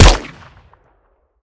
weap_mike_fire_plr.ogg